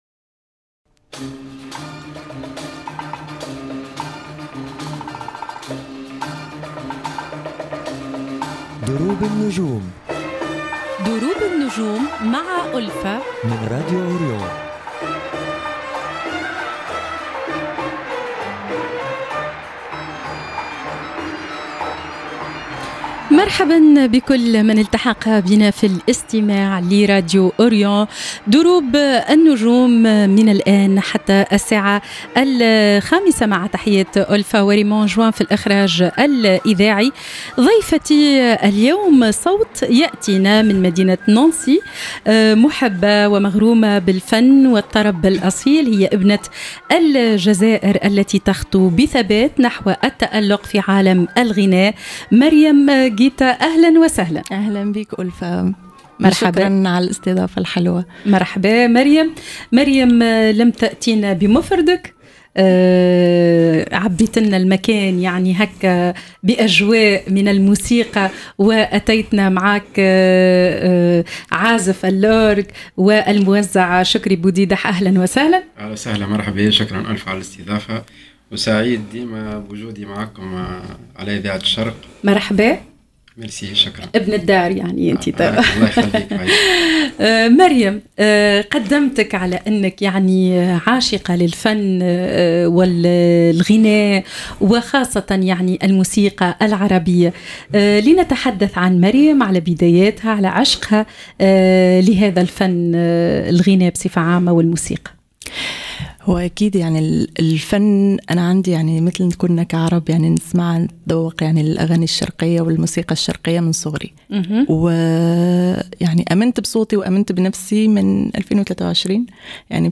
فنانة جزائرية موهوبة بصوت دافئ وإحساس عميق
والذي خاطبها مباشرة على الهواء